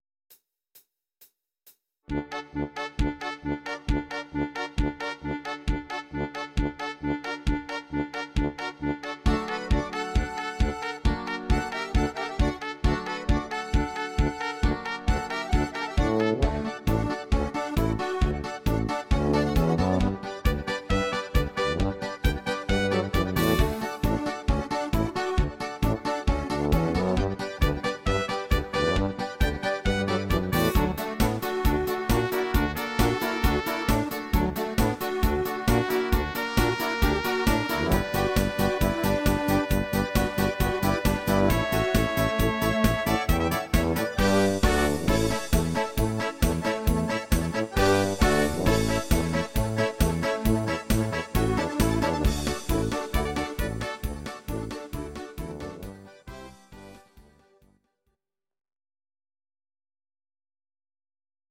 These are MP3 versions of our MIDI file catalogue.
Please note: no vocals and no karaoke included.
Party-Polka version